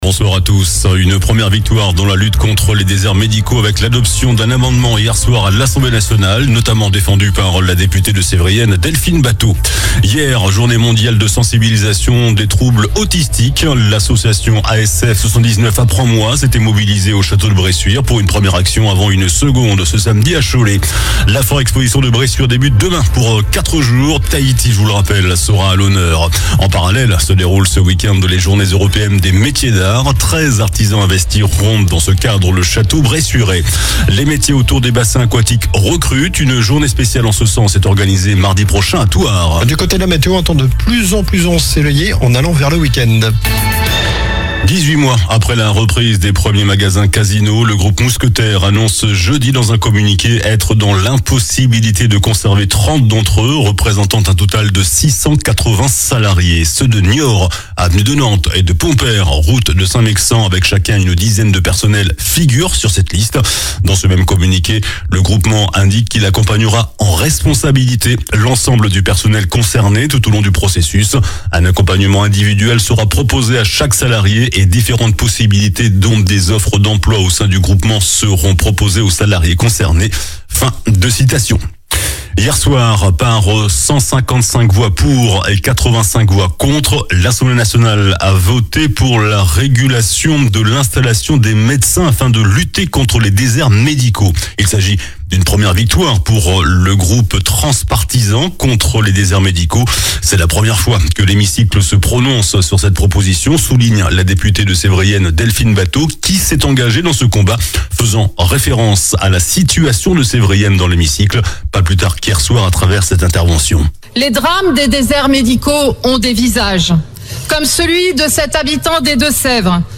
JOURNAL DU JEUDI 03 AVRIL ( SOIR )